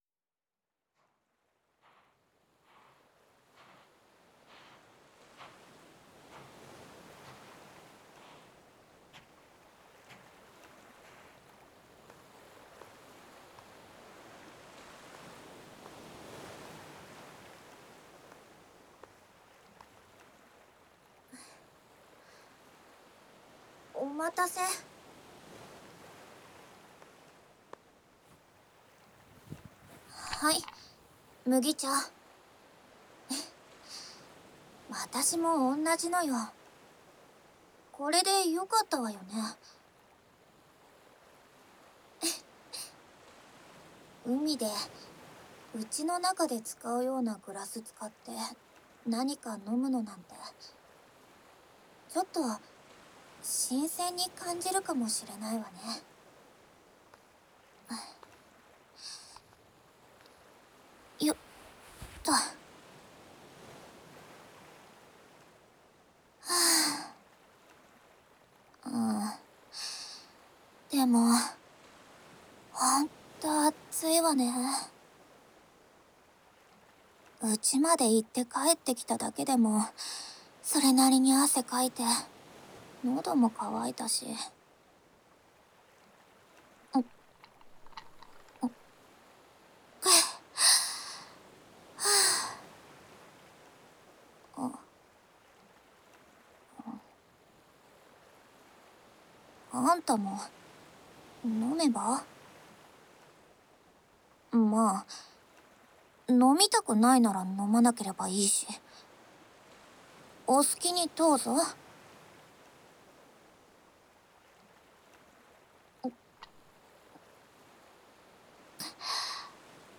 海姉妹・三女 ちょっと素直じゃないツンデレっ娘が波音と共にあなたを癒やすASMR【CV.津田美波】 - ASMR Mirror